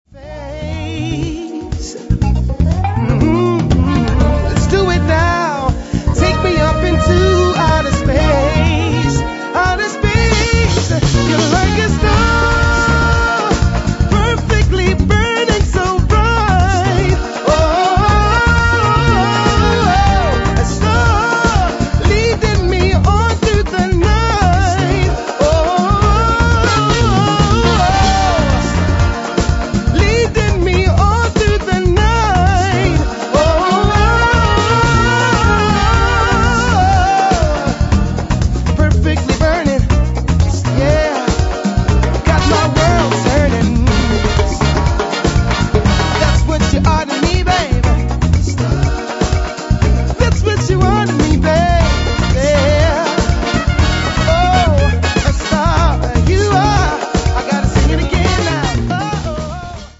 soul bands